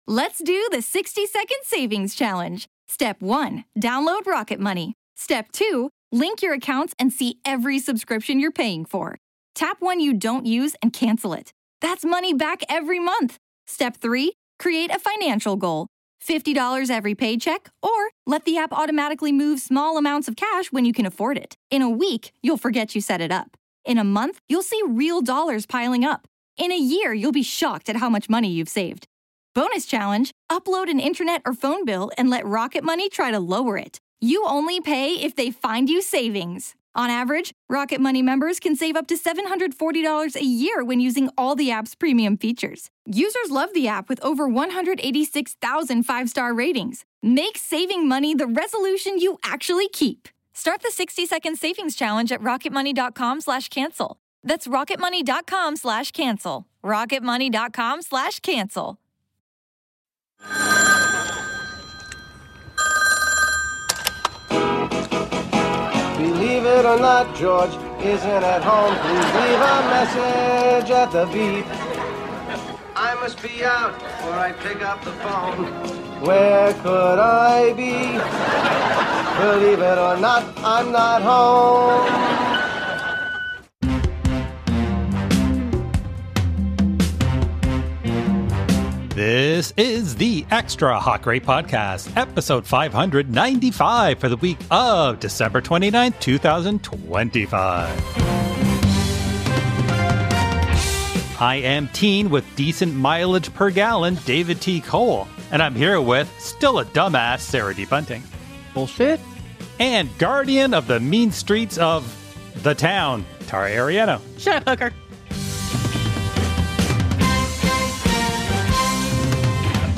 For this special episode, we're returning to the bracket format, but slimmed down for just your three co-hosts: we made up an Elite Eight of TV superheroes -- no one from the DC or Marvel universes allowed -- and argued through which deserved to go all the way.